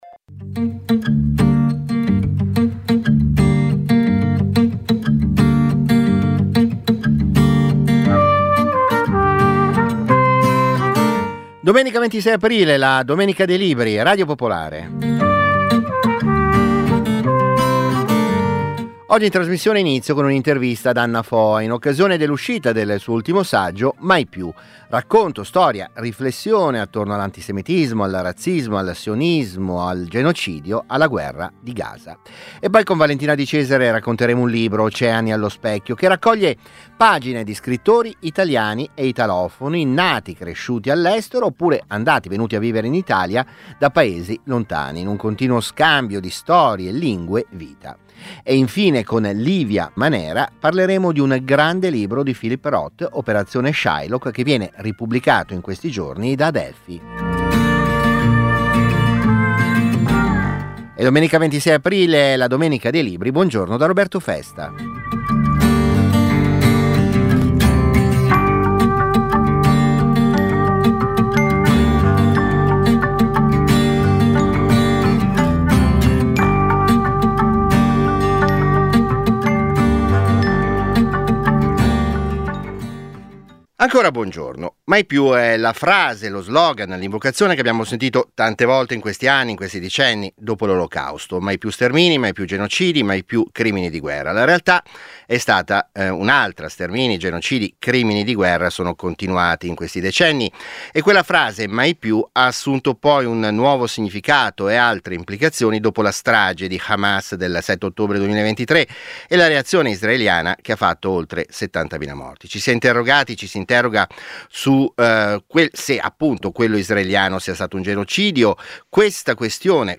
La domenica dei libri è la trasmissione di libri e cultura di Radio Popolare.